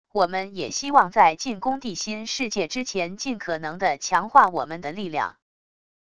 我们也希望在进攻地心世界之前尽可能地强化我们的力量wav音频生成系统WAV Audio Player